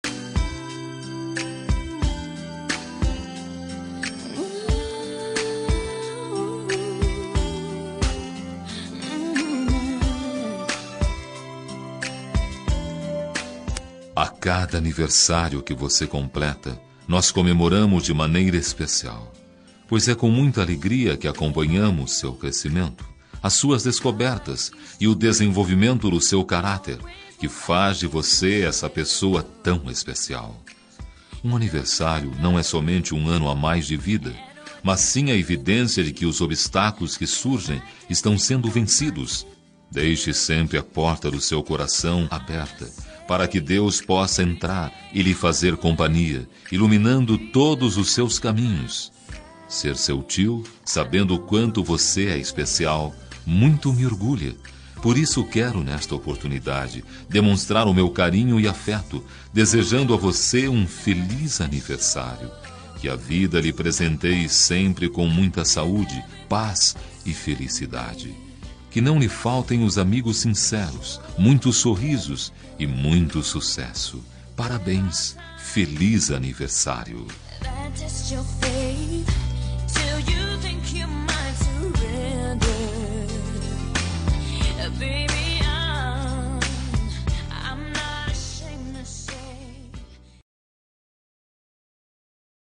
Aniversário de Sobrinho – Voz Masculina – Cód: 2677